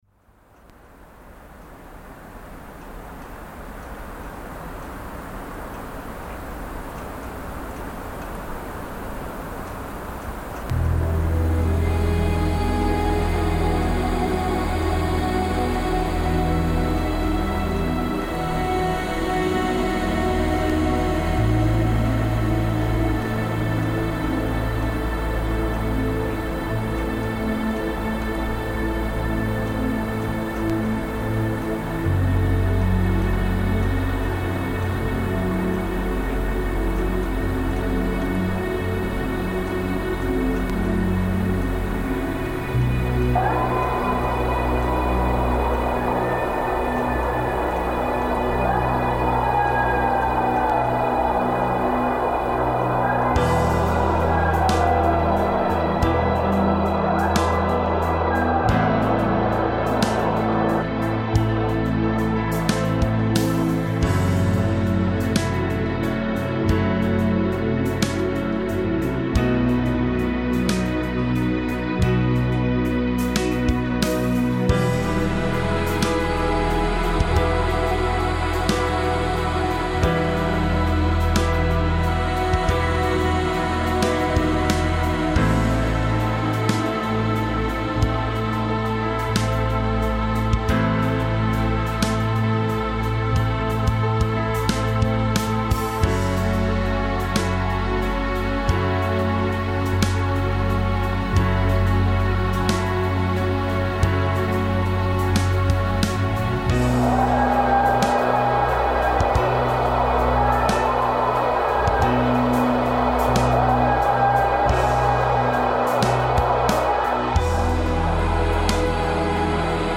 Coyotes and rain in Portland reimagined